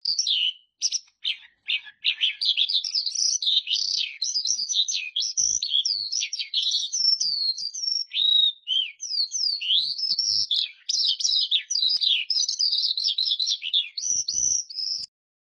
云雀鸣声